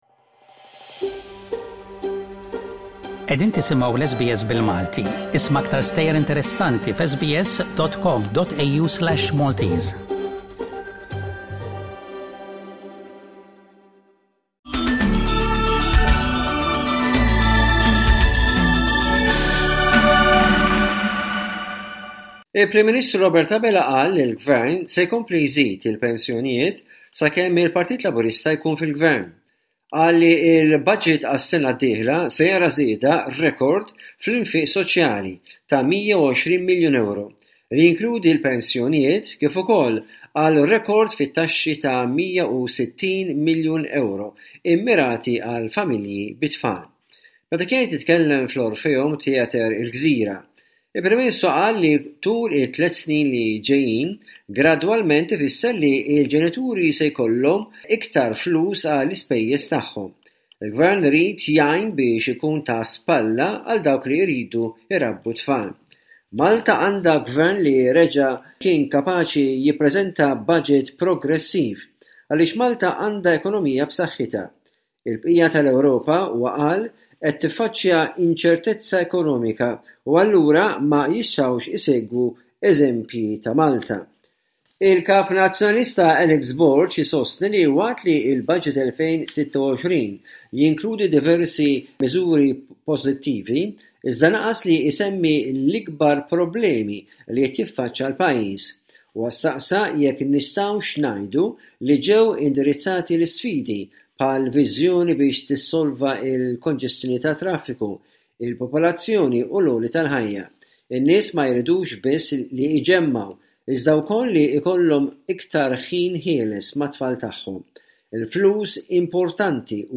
Bullettin ta' aħbarijiet minn Malta mill-korrispondent tal-SBS